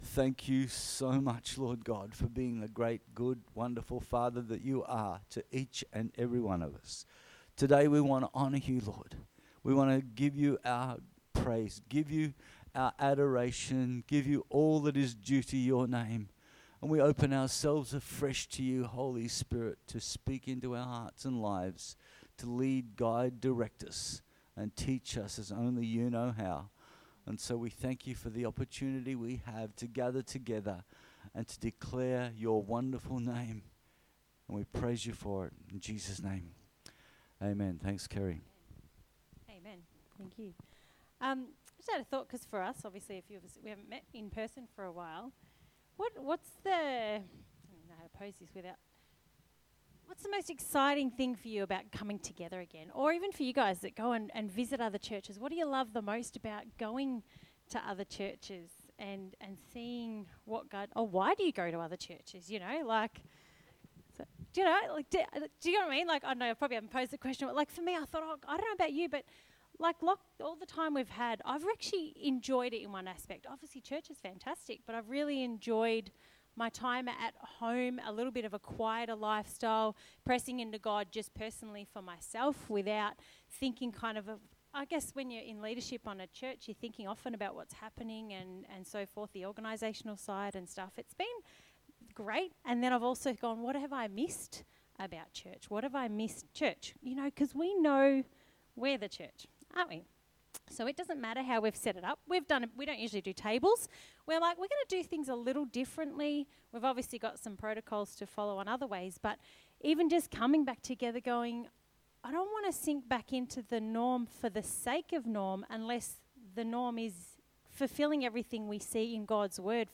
Church Service 29th of Nov 2020